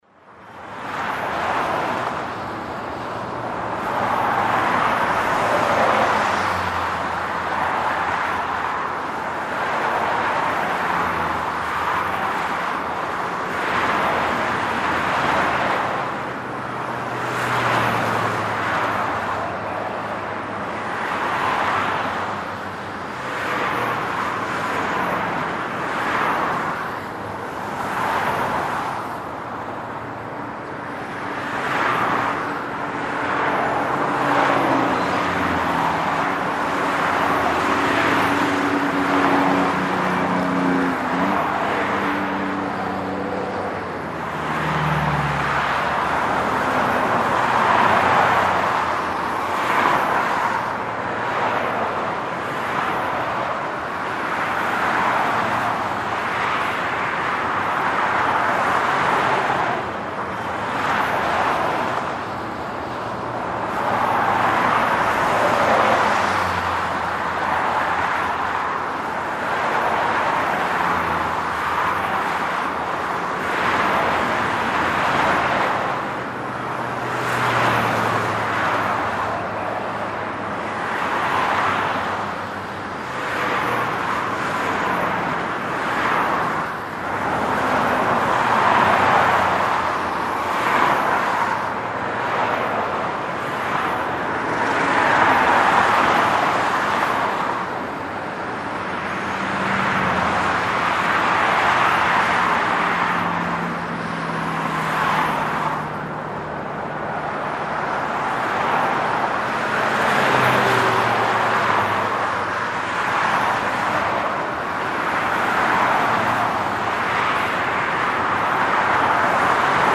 Звуки шоссе, дорог
Дороги заполнены машинами, все спешат в час-пик